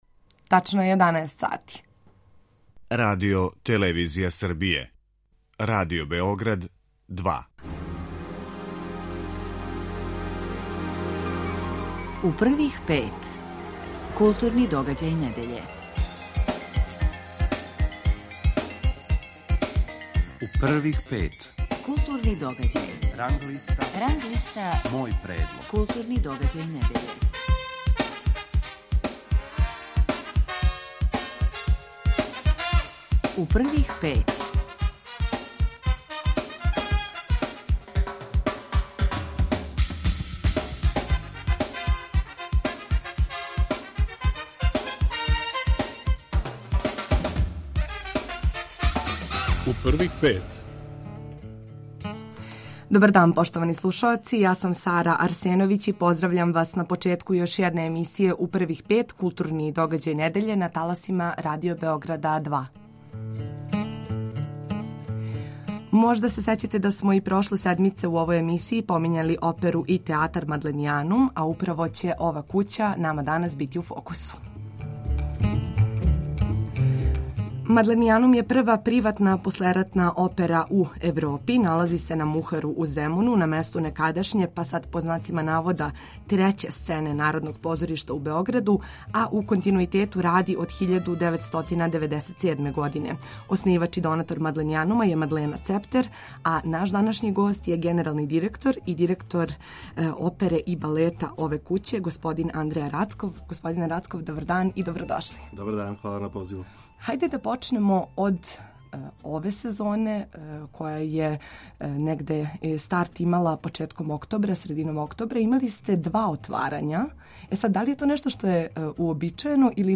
Новинари и критичари Радио Београда 2 издвајају најбоље, најважније културне догађаје у свим уметностима у протеклих седам дана и коментаришу свој избор.